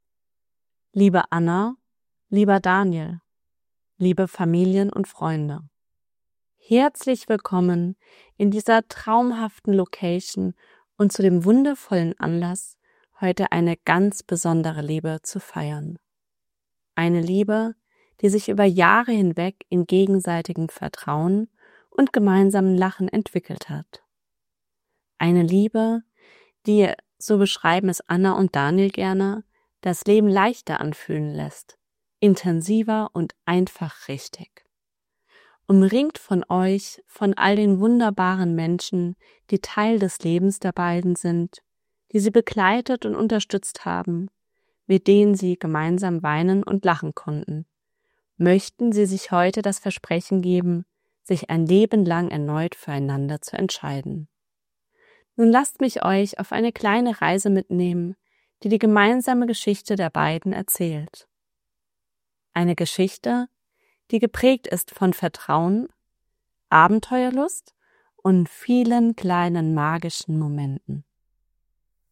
Romantisch & Gefühlvoll
Wir feiern eure Verbindung leise, kraftvoll und mit Gänsehaut-Garantie.